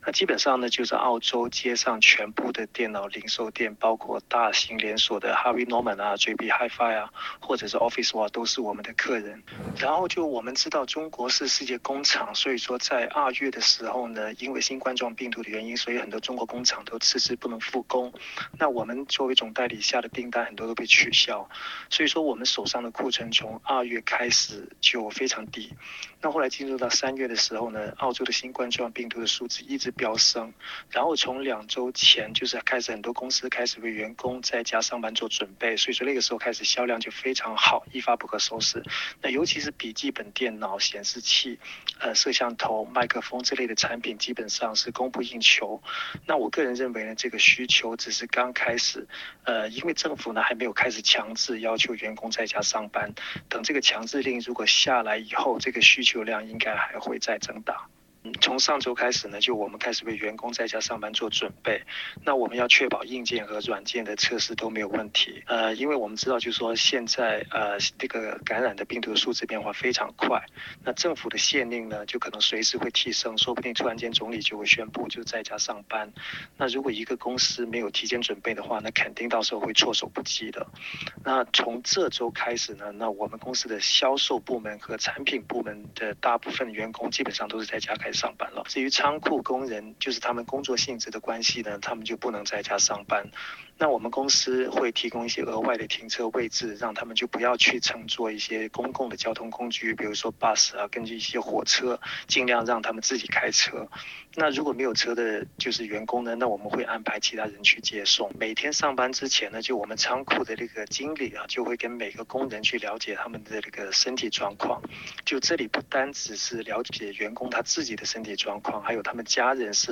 点击上方图片收听采访录音。